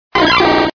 Cri de Qwilfish dans Pokémon Diamant et Perle.